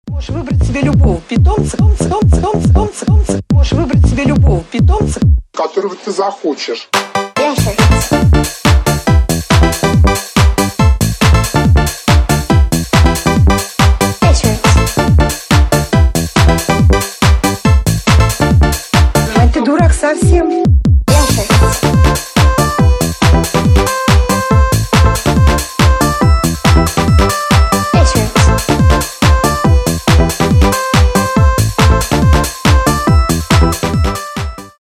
2024 » Русские » Поп » Тик Ток Скачать припев